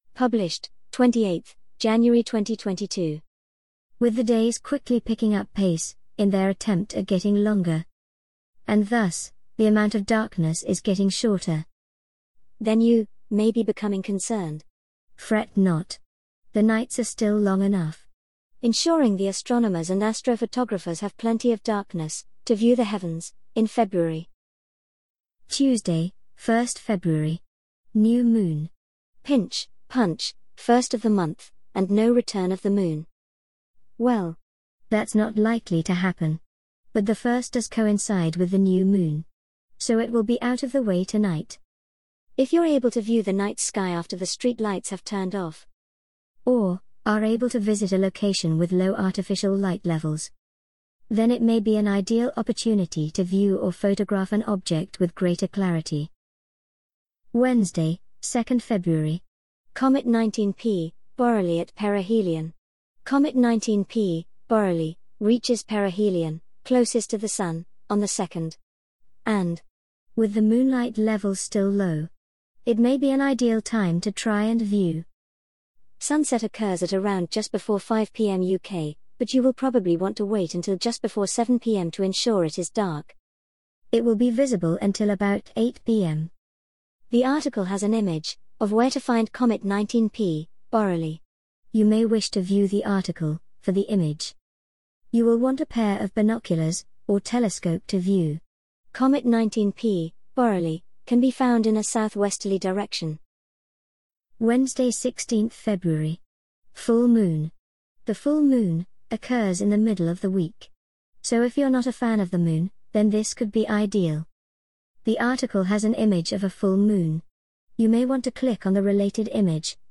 An audio reading of the Realm of Darkness February 2022 Article